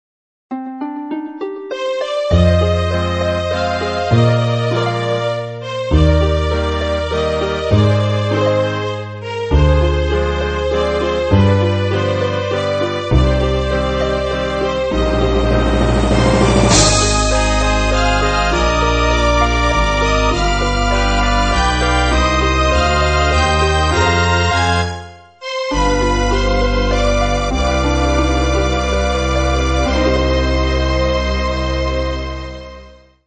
お遊びなので、パート数も最低限で、音色やボリュームも適当です。
（ヘッドホンで音量調整しているのでスピーカーで聴くと低音が小さいはずです・・・）
027 　韓国ドラマ風（Cm）（そのもの？）